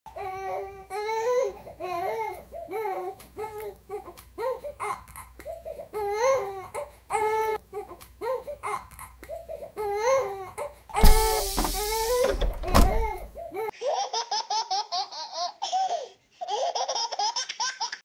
Pregnant Humaniod Robot Testing sound effects free download